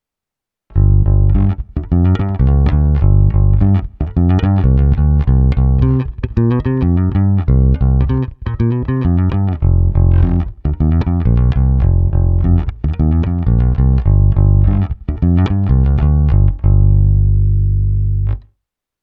Ne tak zvonivý, ale zato parádně pevný, tučný, a to i s přihlédnutím k tomu, že jsem basu dostal s hlazenkami s nízkým tahem.
Simulace XLR výstupu zesilovače Ampeg